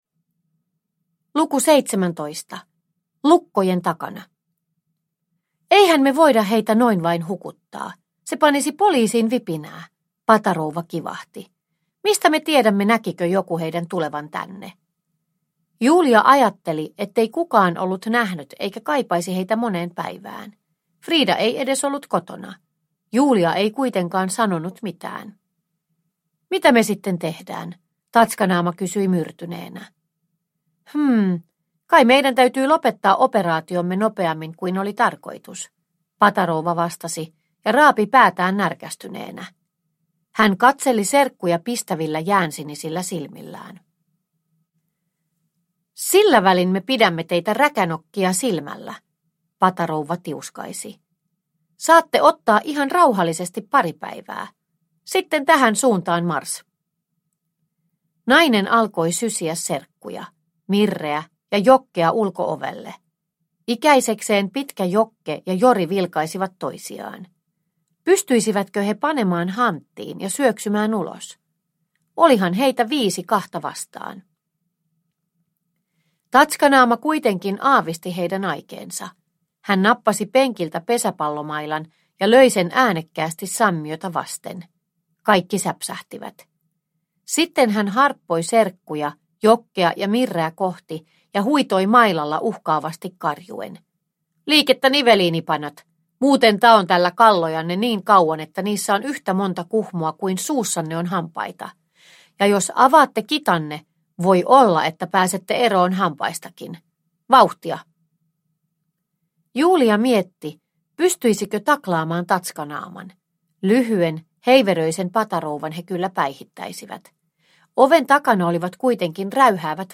Roistoja ja roskaajia – Ljudbok – Laddas ner